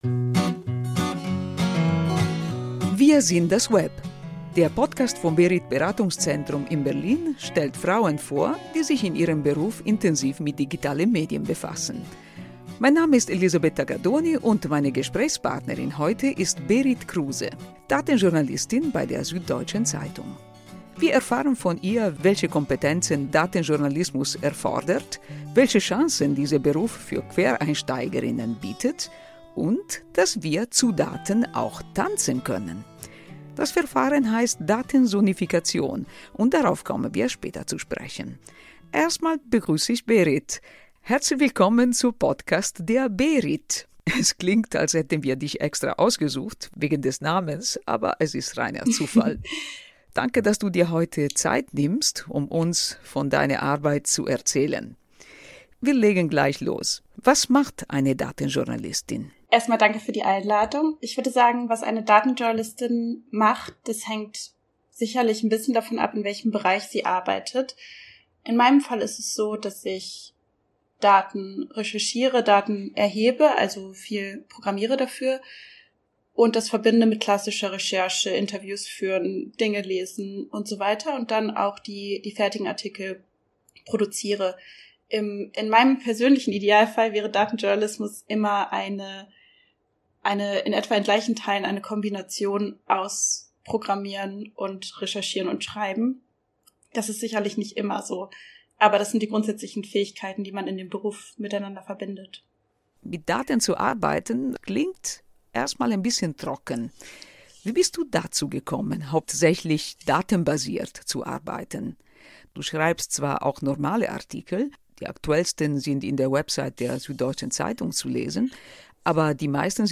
Frauen aus Web-, Kommunikations- und Kreativberufen im Gespräch Podcast